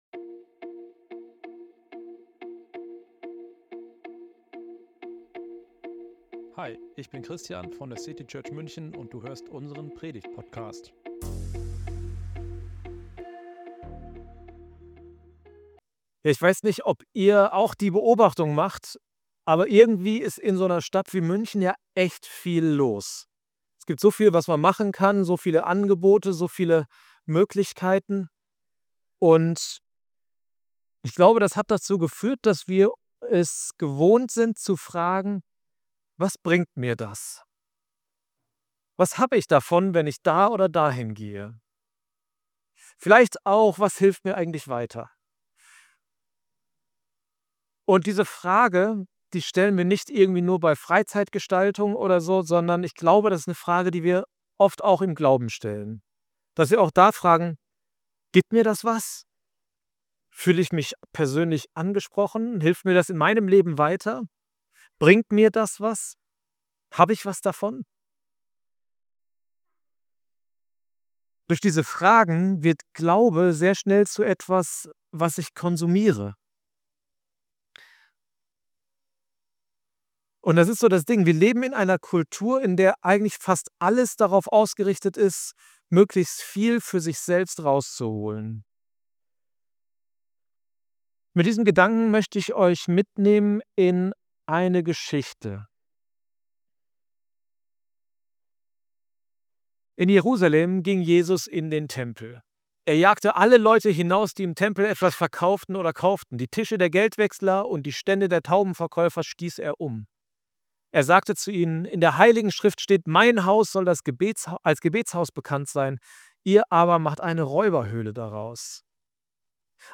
Was bedeutet das für unseren Glauben – und wie wird aus Konsum wieder Beteiligung? Predigt aus unserem Abendmahls-Gottesdienst.